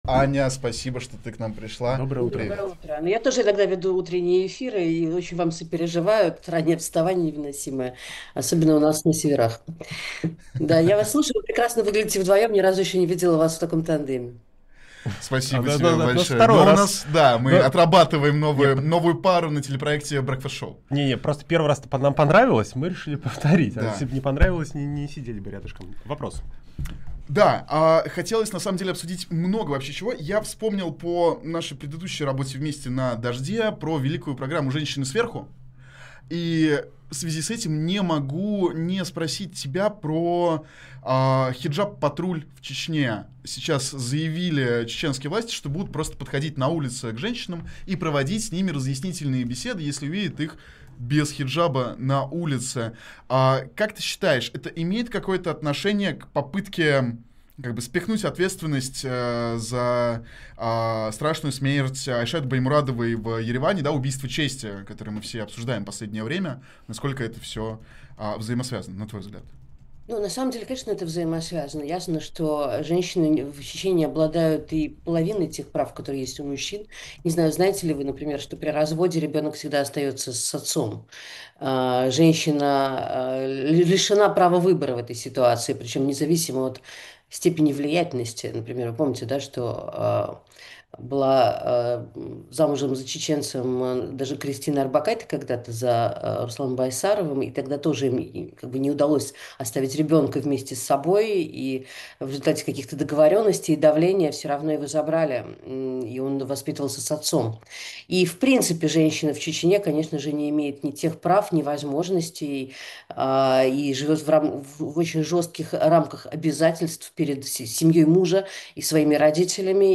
Фрагмент эфира от 26 октября